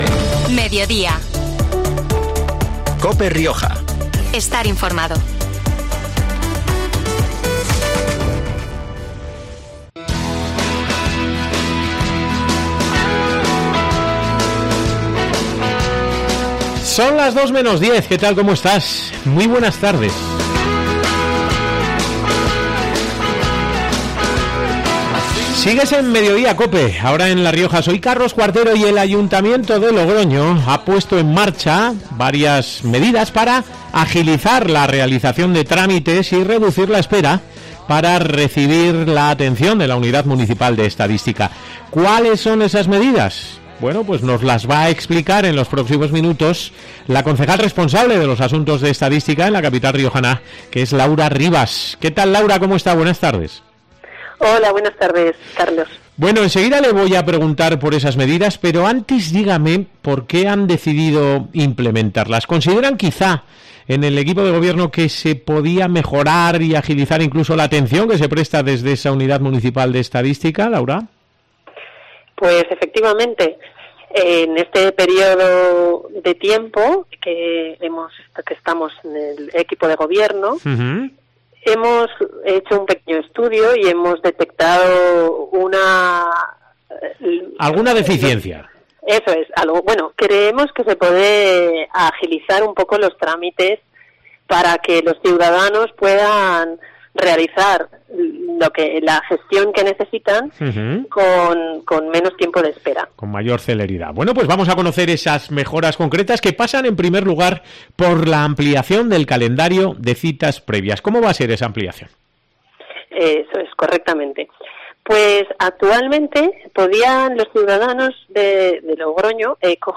Tal y como ha explicado este 27 de julio en COPE Rioja la concejala responsable de los asuntos de Estadística, Laura Rivas, el paquete de medidas implica la ampliación del calendario de citas previas, la atención especializada en el Servicio 010 para consultas relacionadas con el empadronamiento, la mejora de los plazos y trámites a la hora de solicitar certificados, la ampliación del personal de la Unidad Municipal de Estadística y la puesta en marcha de una campaña informativa sobre las nuevas medidas.